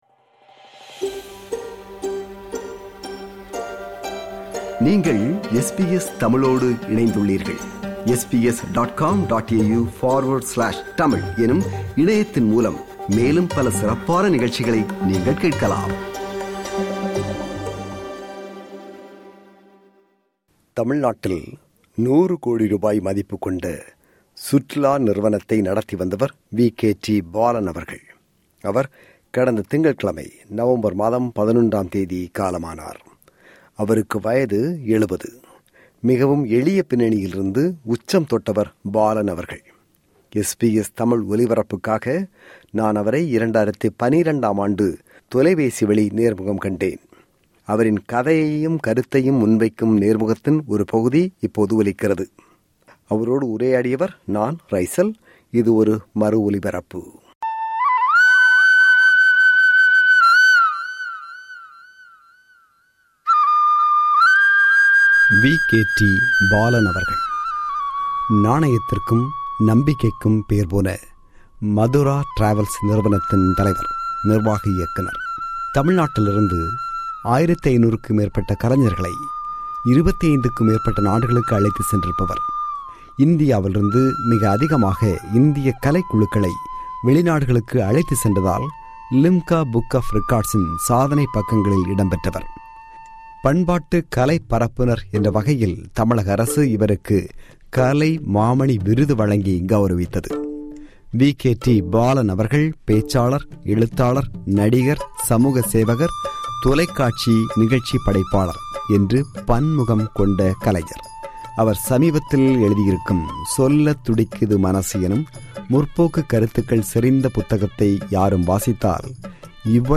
SBS தமிழ் ஒலிபரப்புக்காக நான் அவரை 2012 ஆம் ஆண்டு தொலைபேசி வழி நேர்முகம் கண்டேன்.
இது ஒரு மறு ஒலிபரப்பு.